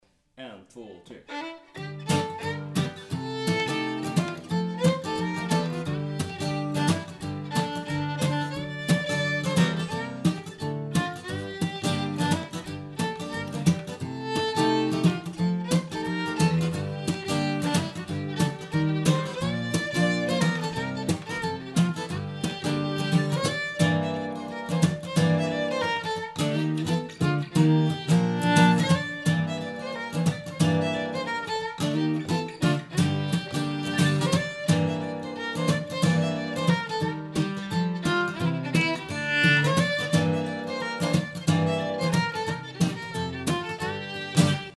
En enkel lite schottis i G-moll.
Melodi och komp